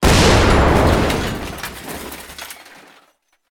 combat / weapons / rocket / metal3.ogg
metal3.ogg